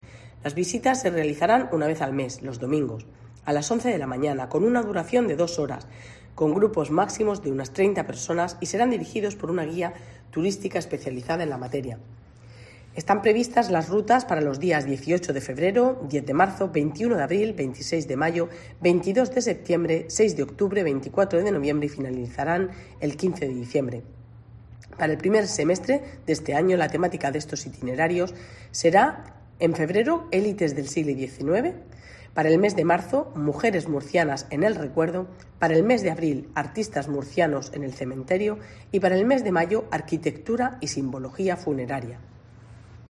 Pilar Torres, concejala de Bienestar Social, Familia y Salud